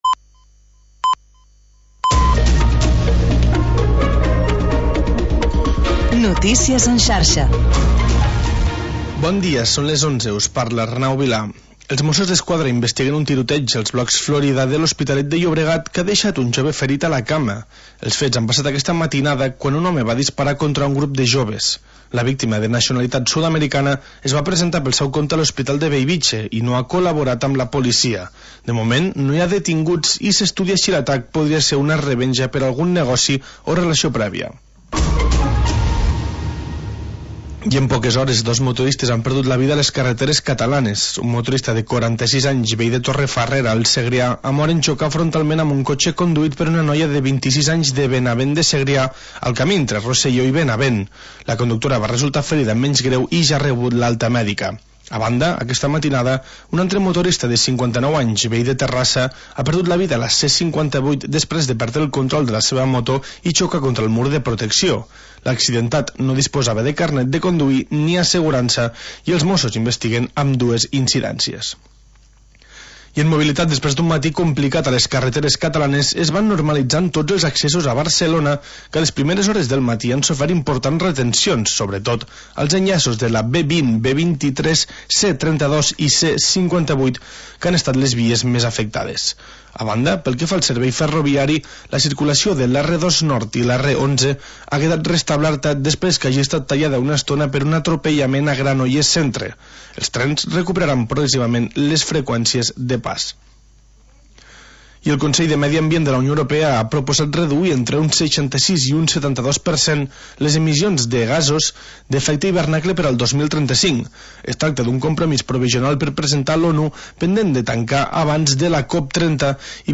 Magazín d'entreteniment per encarar el dia